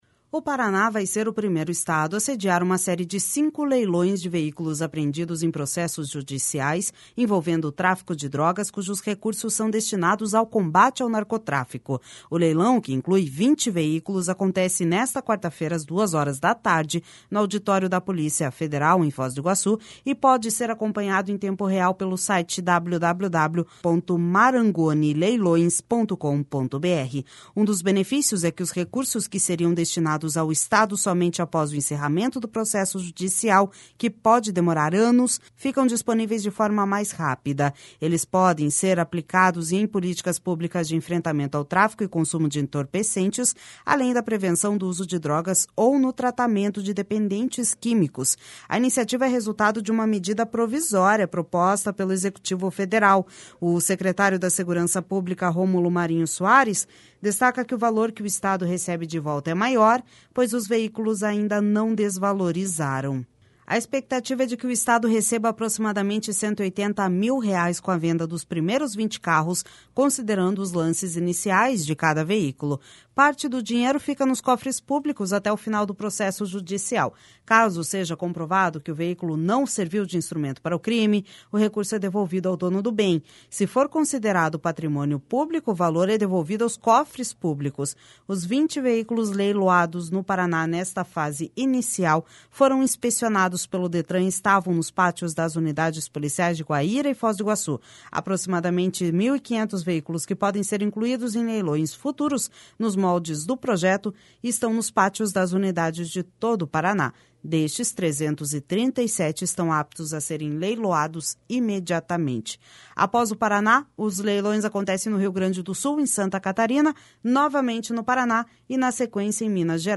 O secretário da Segurança Pública, Romulo Marinho Soares, destaca que o valor que o Estado recebe de volta é maior, pois os veículos ainda não desvalorizaram.